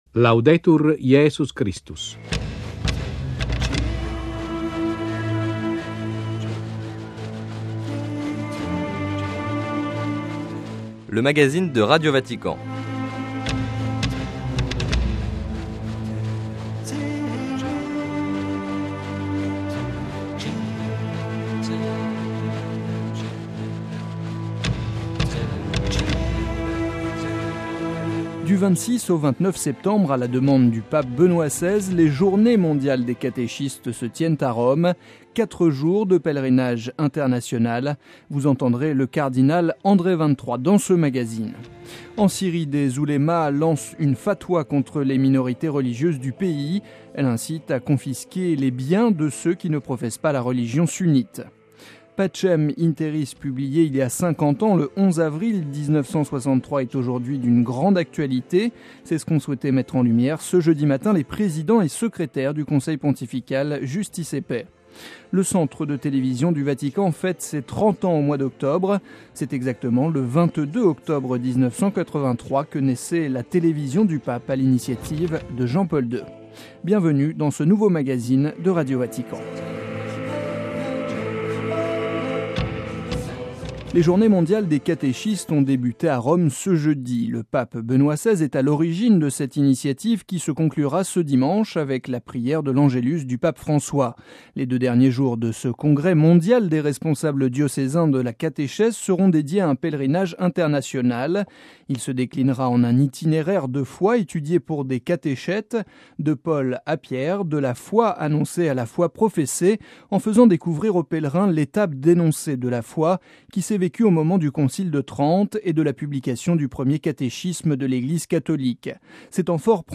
Vous entendrez le cardinal André Vingt-Trois - Des responsables religieux islamiques incitent à la violence interconfessionnelle en Syrie.